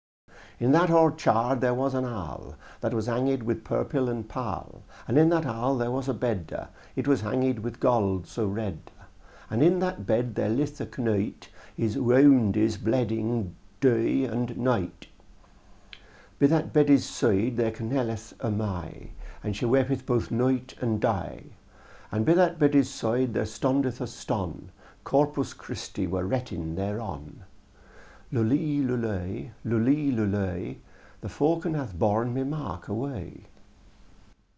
how academics believe that this version may have sounded around the time it was written.